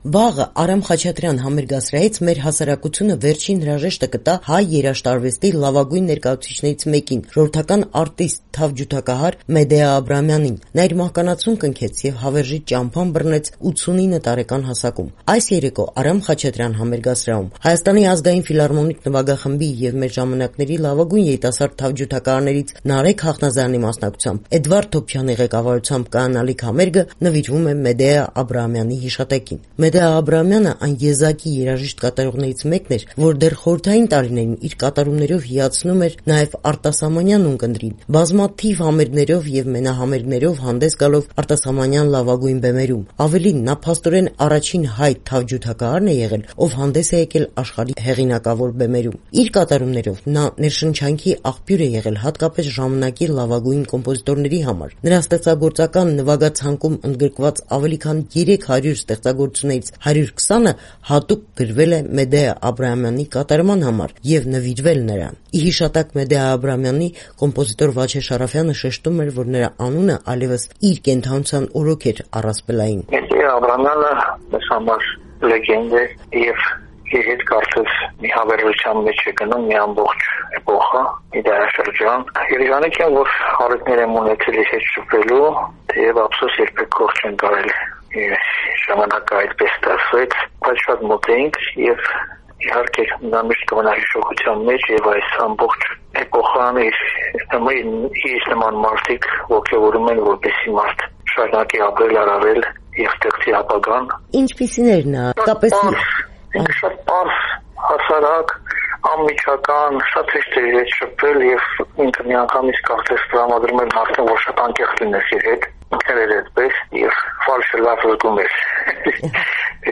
Ռեպորտաժներ
Մեդեա Աբրահամյանի հիշատակին նվիրված միջոցառում Արամ Խաչատրյան համերգասրահում